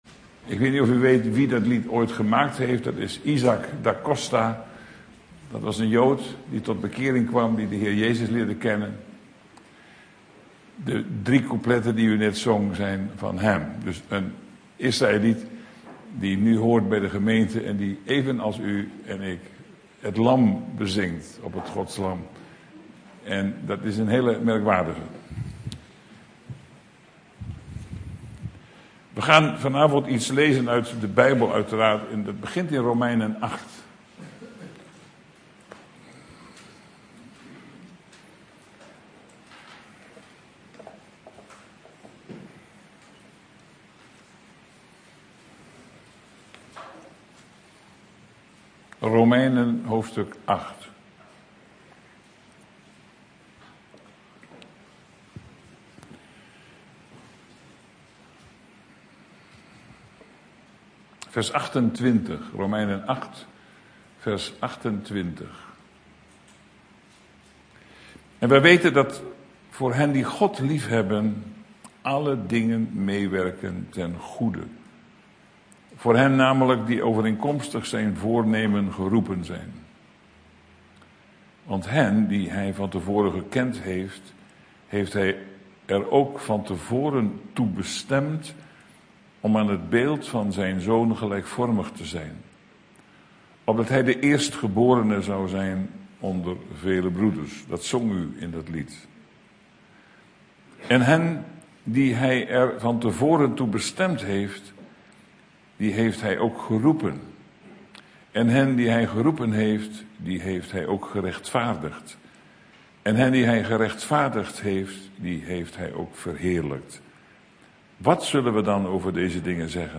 Deze preek is onderdeel van de serie: